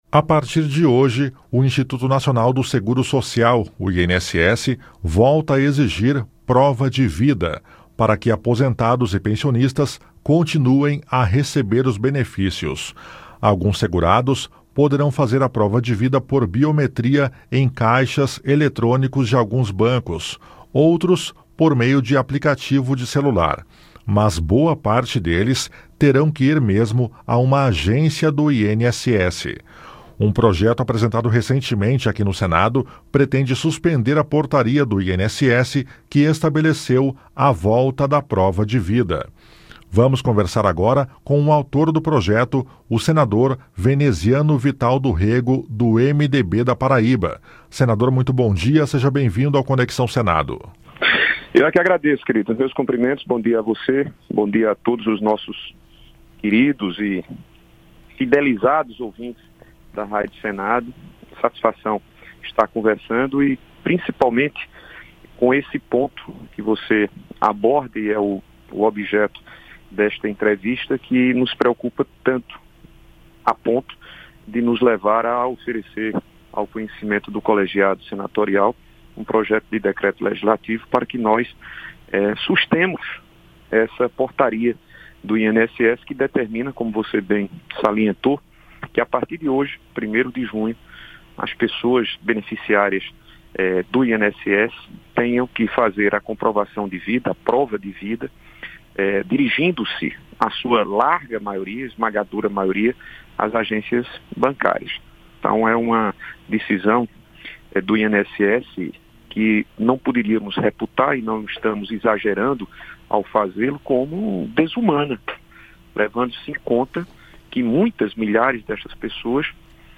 Saiba mais na entrevista que ele concedeu ao "Conexão Senado" nesta terça-feira (1º).